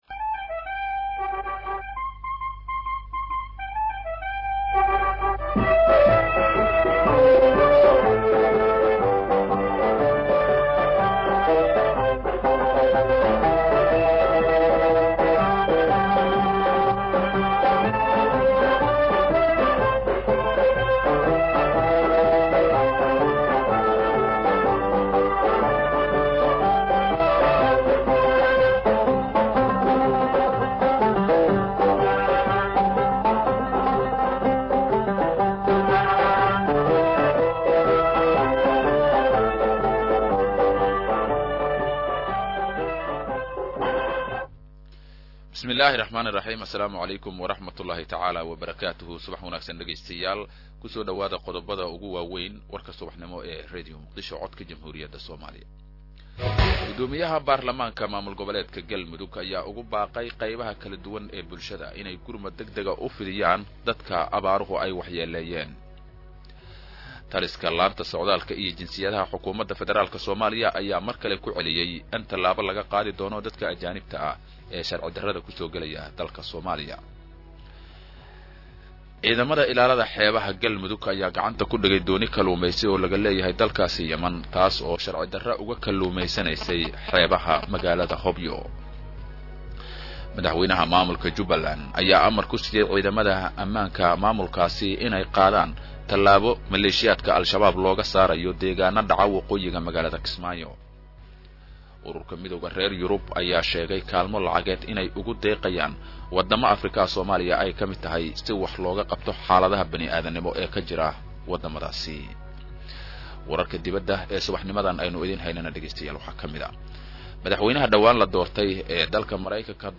Dhageyso Warka Subax Ee Radio Muqdisho 8-1-2017